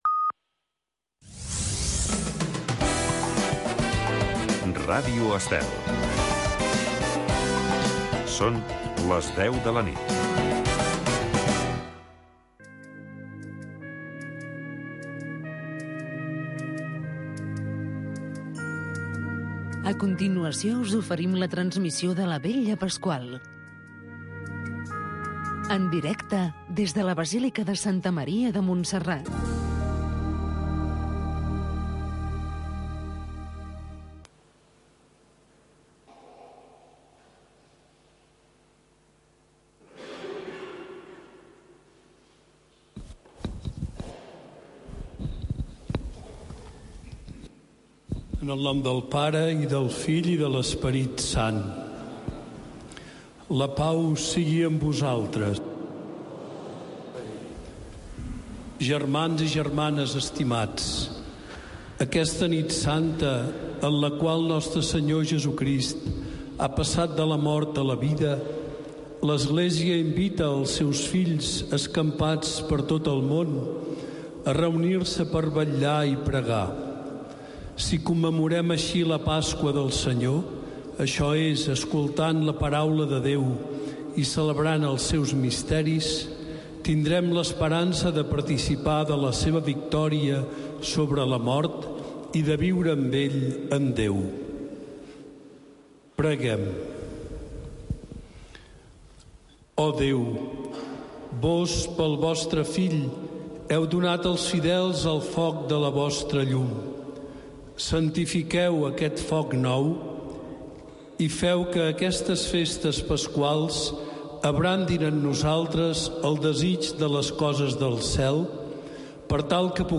Retransmissió en directe de la missa conventual des de la basílica de Santa Maria de Montserrat.